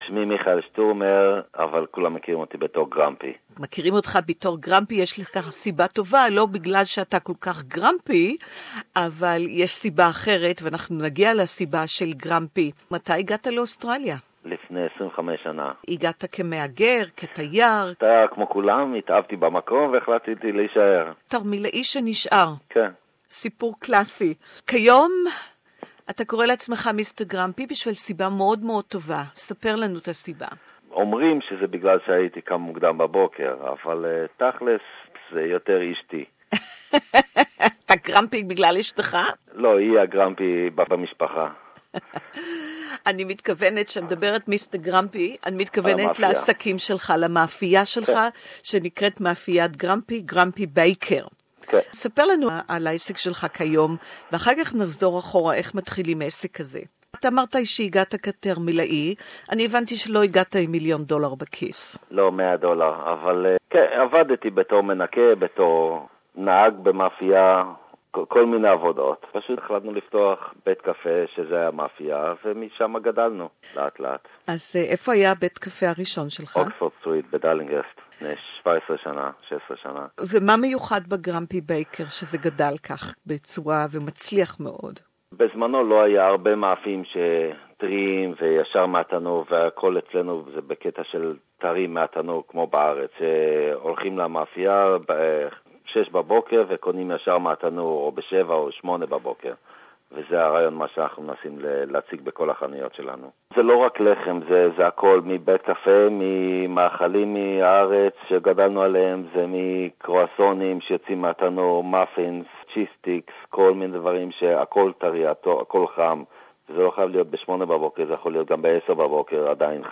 Did you know that little Israel exists in Vaucluse? Interview in Hebrew